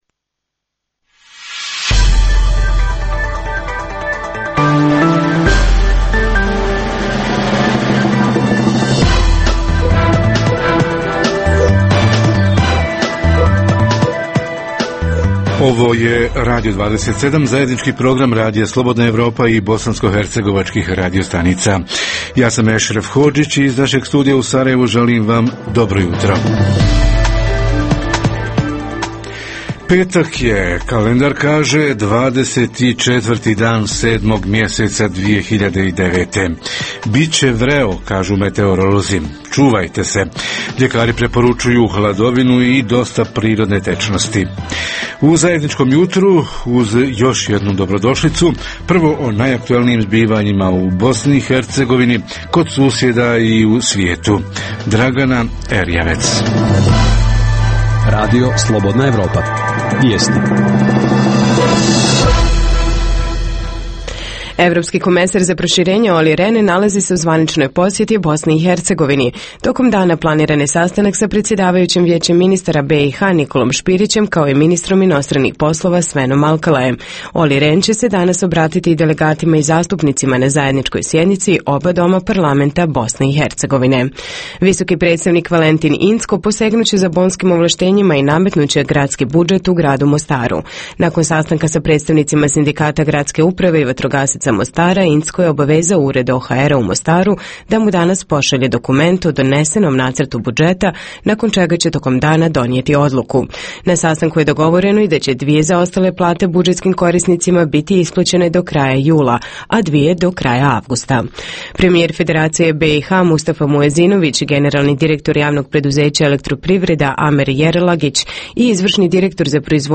Tema jutra su ljetne vreline i zdravlje – preventiva protiv trovanja hranom Redovna rubrika Radija 27 petkom je “Za zdrav život". Redovni sadržaji jutarnjeg programa za BiH su i vijesti i muzika.